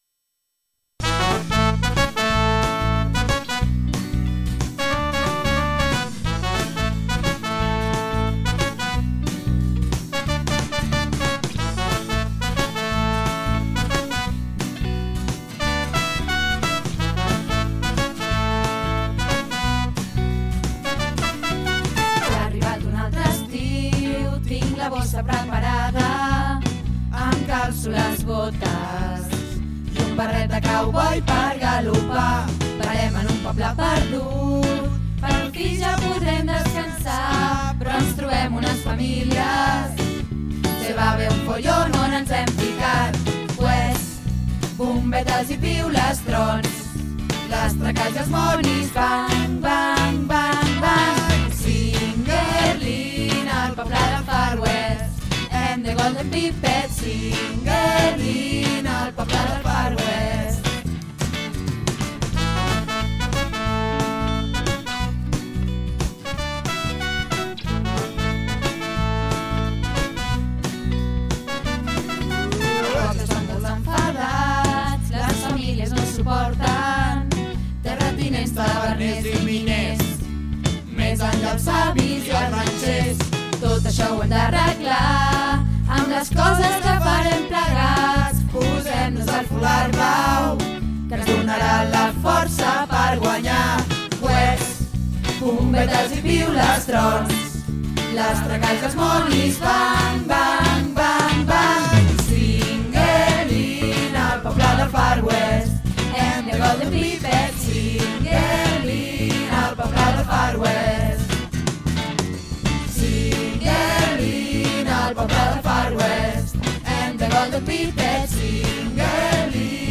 Dom Bosco 2014